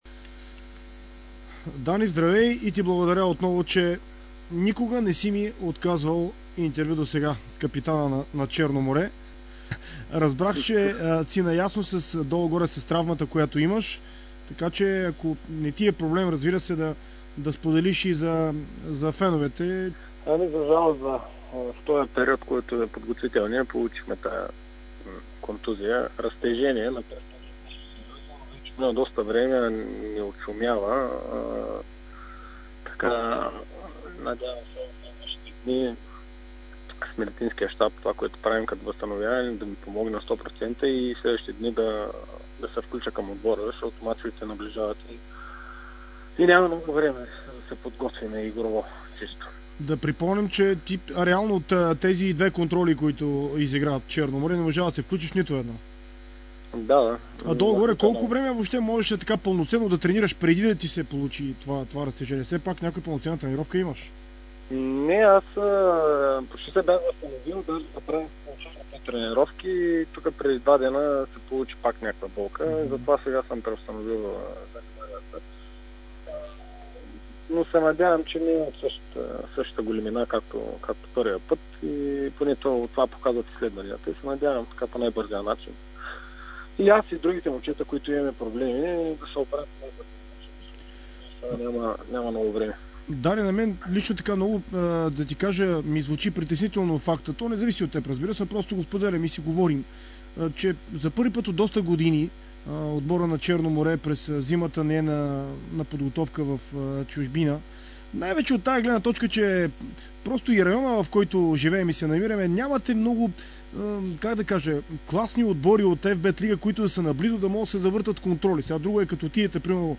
интервю за Дарик и dsport